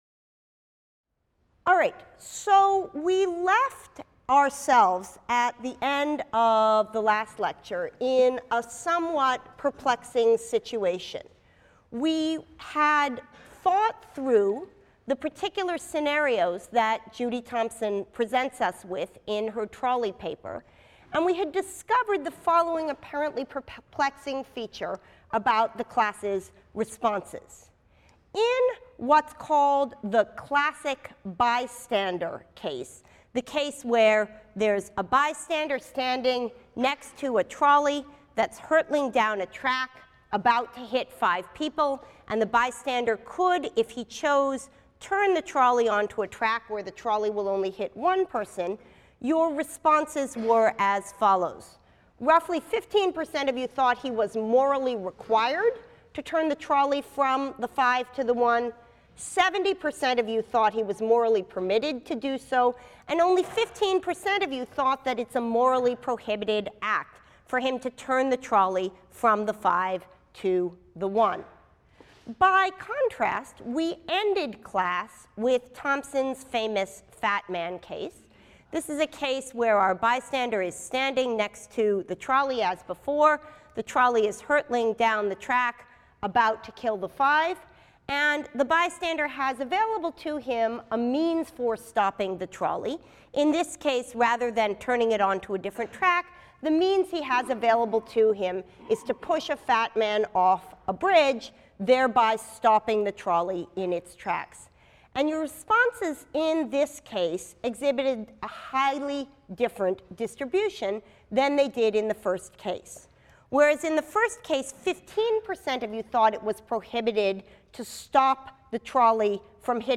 PHIL 181 - Lecture 15 - Empirically-informed Responses | Open Yale Courses